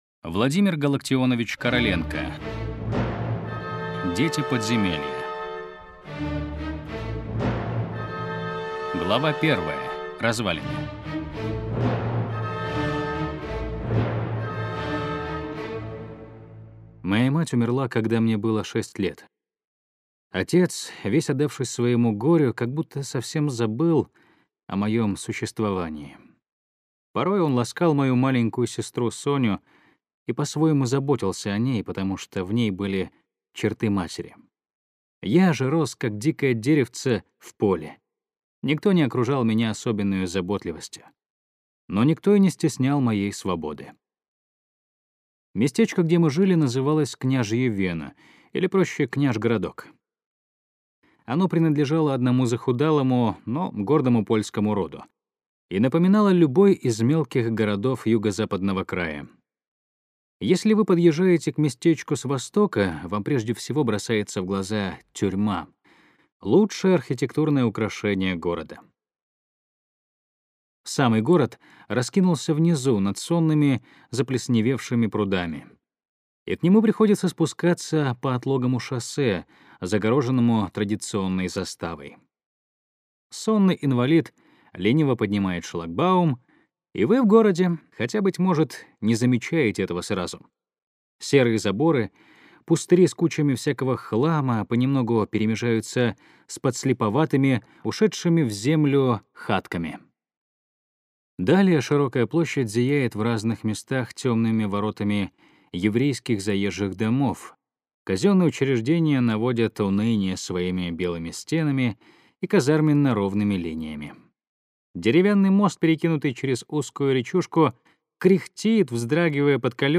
Аудиокнига Дети подземелья - купить, скачать и слушать онлайн | КнигоПоиск